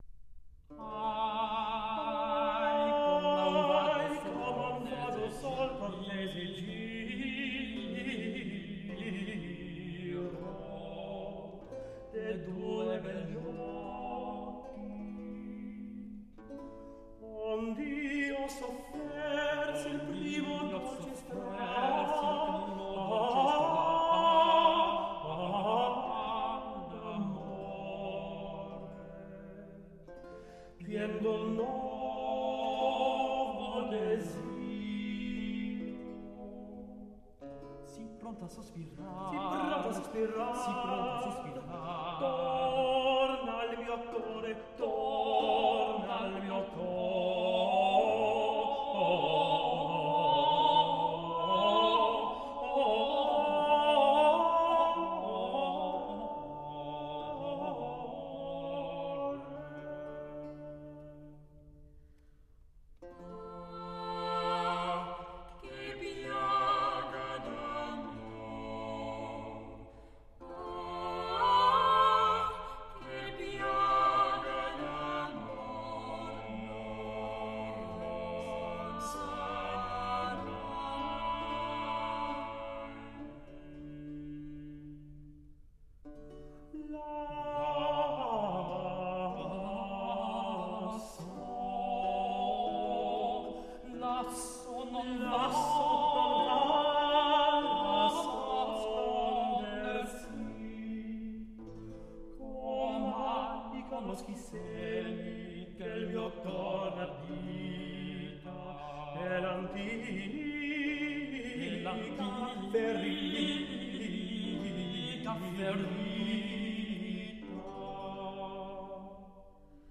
Monteverdi, Ahi, come a un vago sol (madrigale Libro V).mp3